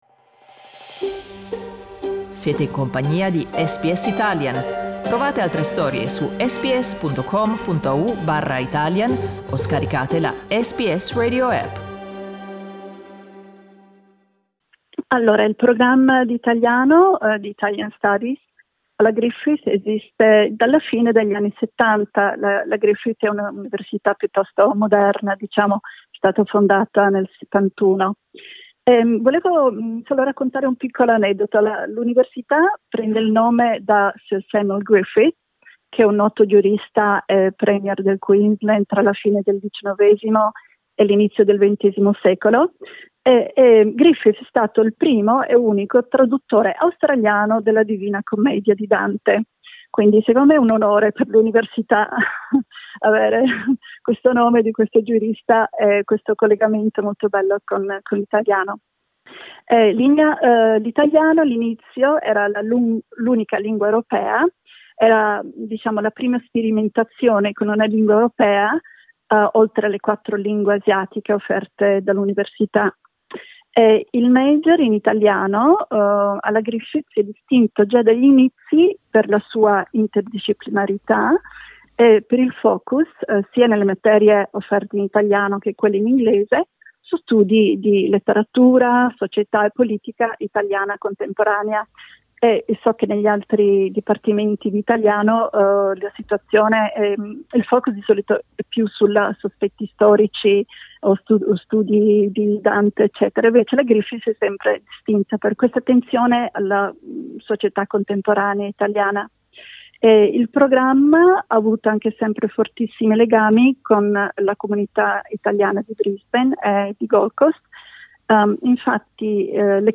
Ascolta l'intervista: LISTEN TO Laurea di italiano online alla Griffith University SBS Italian 12:34 Italian Le persone in Australia devono stare ad almeno 1,5 metri di distanza dagli altri.